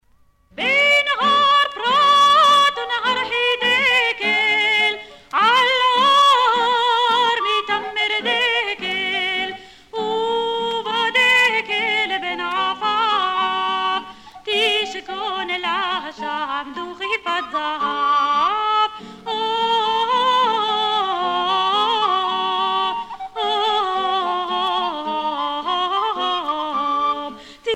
Influence du folklore sépharade (judéo-espagnol et oriental)